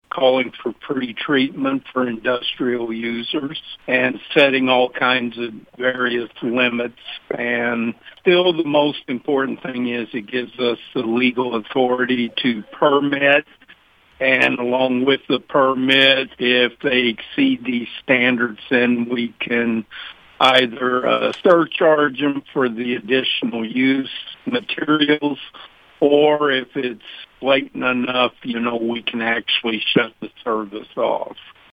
City Administrator Dale Klussman says the ordinance also gives the city more legal authority to enforce its pretreatment regulations.